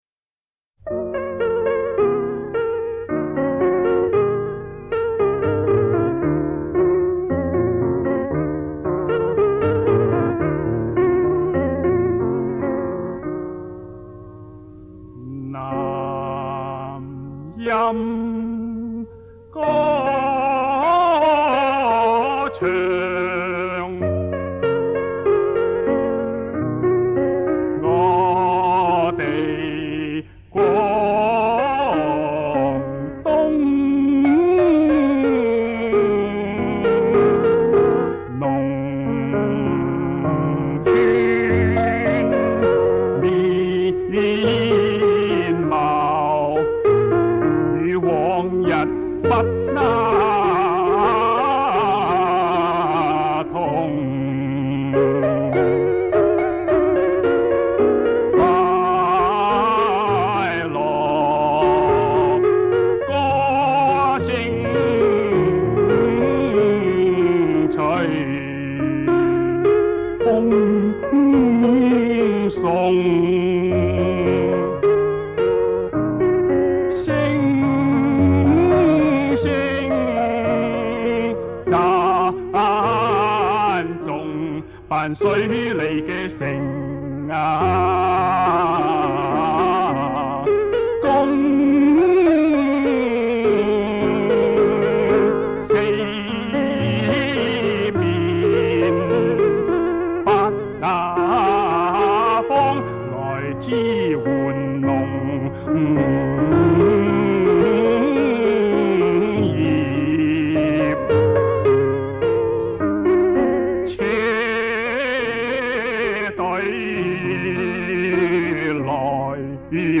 男声版的比较少见。